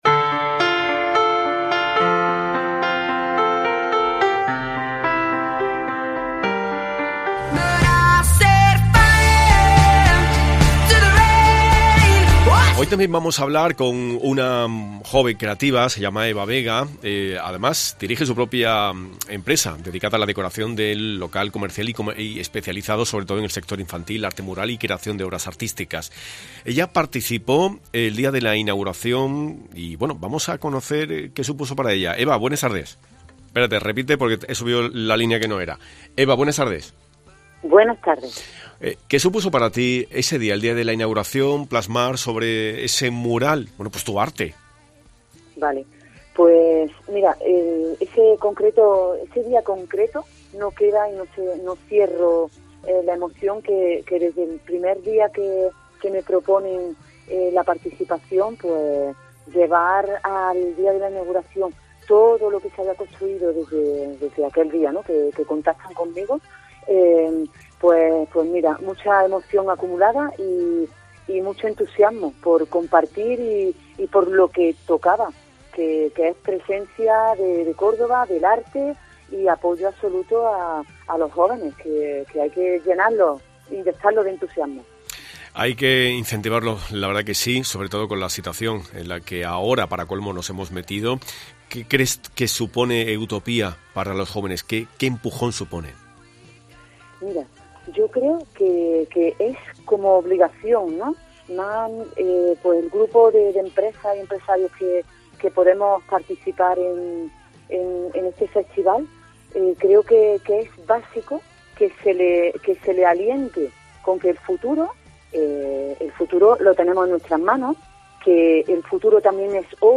Escucha a Cintia Bustos, concejal de Juventud en el Ayuntamiento de Córdoba